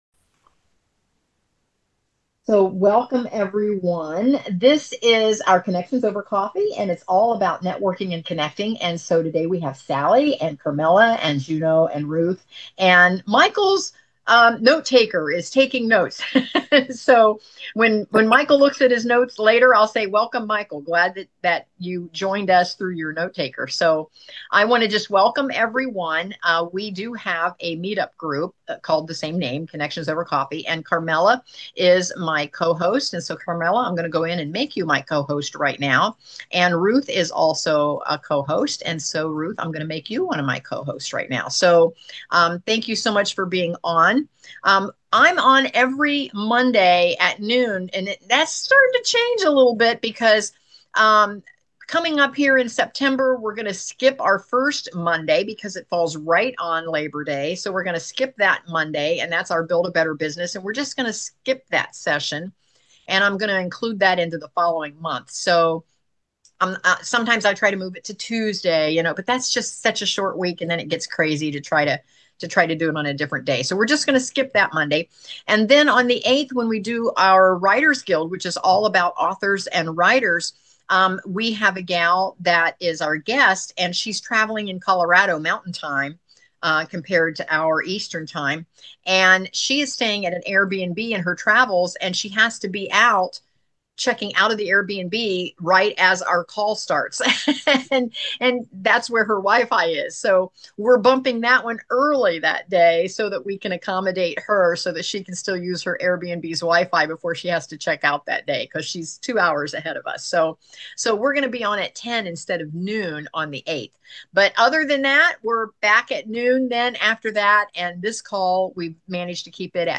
✨ If you couldn’t join us live, you’re in for a treat—this session was packed with inspiration, laughter, and soul-filling conversation!